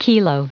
Prononciation du mot kilo en anglais (fichier audio)
Prononciation du mot : kilo